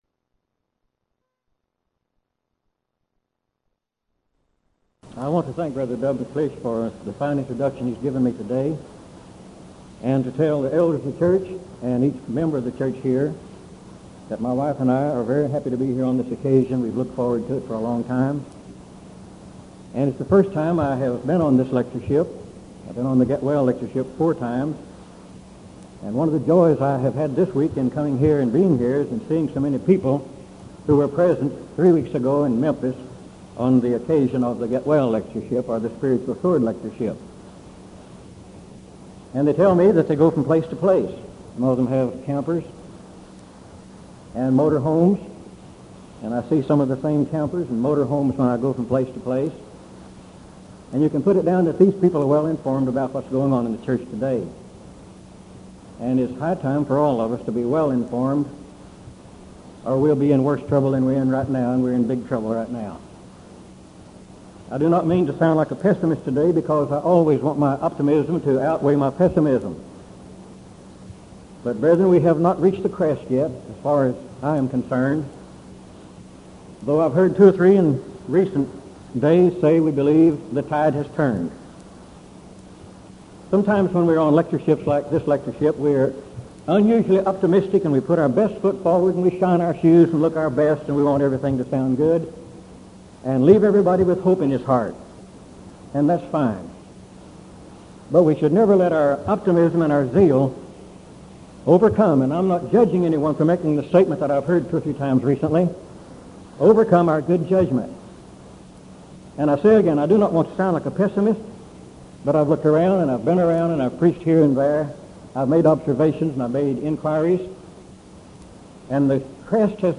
Event: 1986 Denton Lectures
lecture